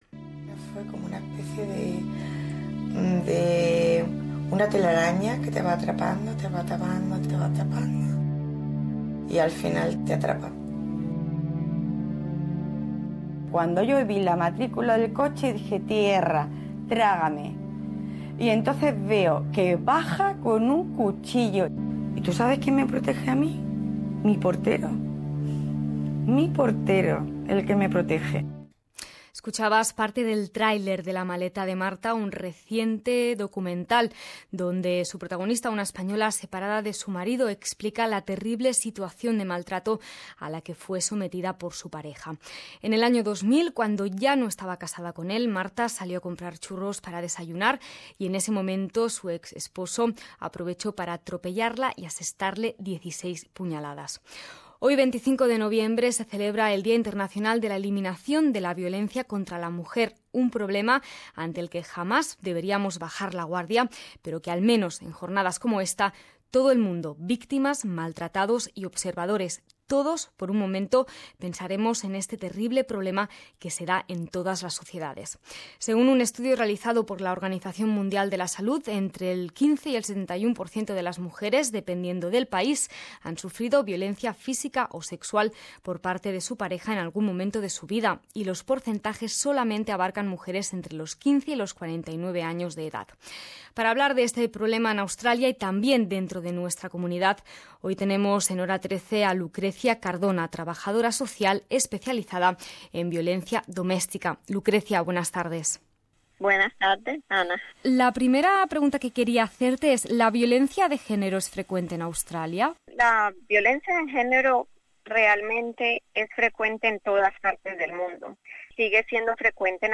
Durante la entrevista la trabajadora social nos contó que existen muchos modos de violencia, más allá del maltrato físico, y que cualquier forma de controlar y disminuir el poder de tu pareja se considera violencia doméstica.